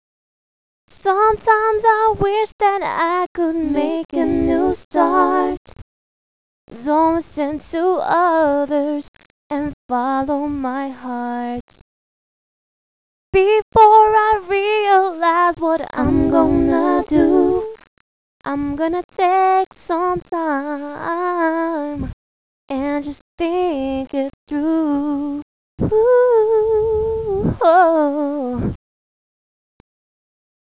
ORIGINAL SONGS (acapella)
• 'THINK IT THROUGH' (harmonies all sung by me!!!)
This is kind of Pop as well....